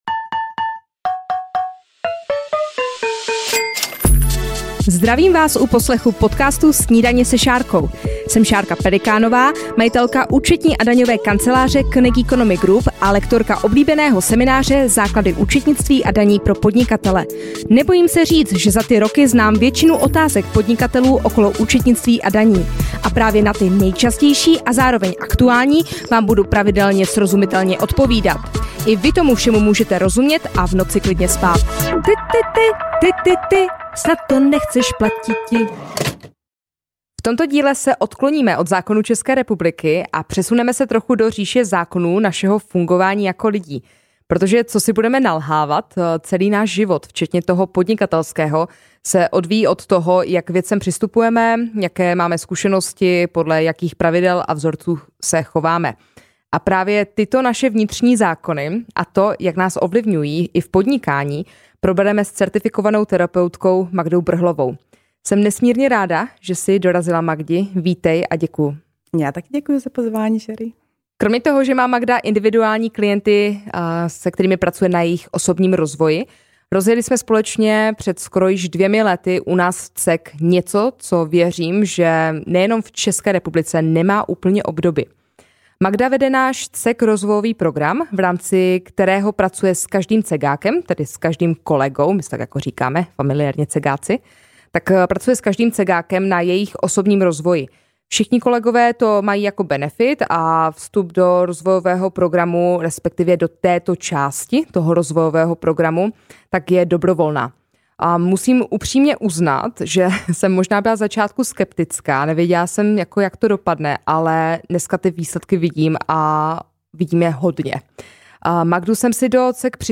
Tématem dnešního rozhovoru pro změnu nebudou zákony České republiky, ale naše vnitřní zákony a to, jak ovlivňují nás a naše podnikání.